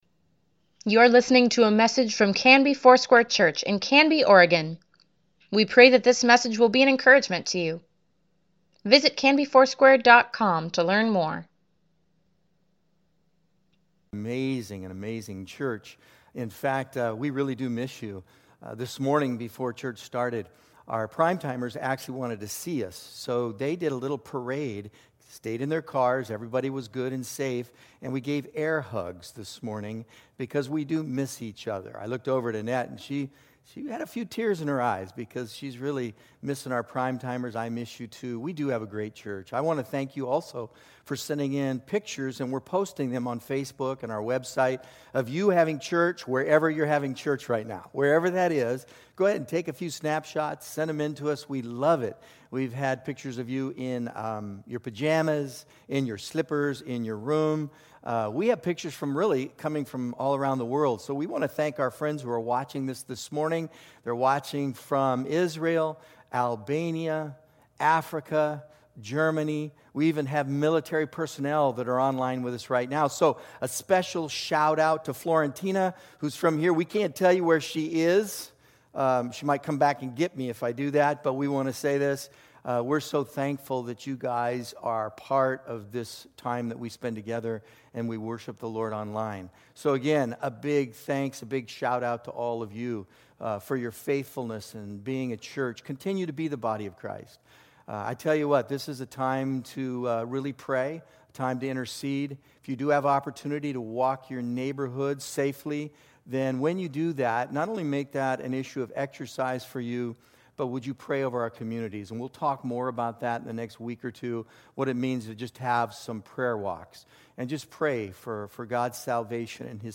Weekly Email Water Baptism Prayer Events Sermons Give Care for Carus Palm Sunday April 5, 2020